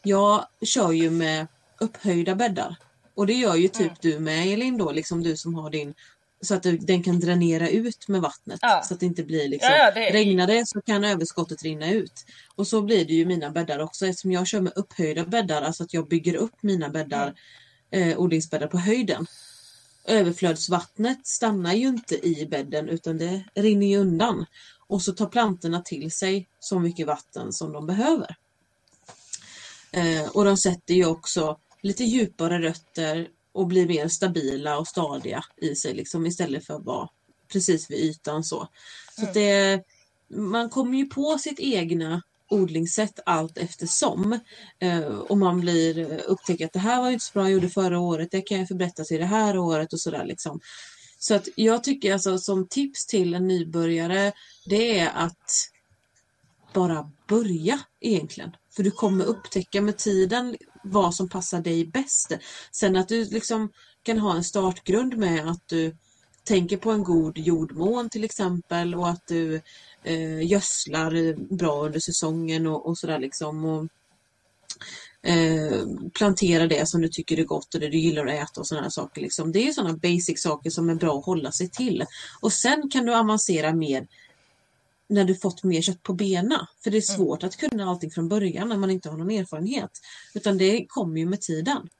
Radio Regeringen diskuterade odling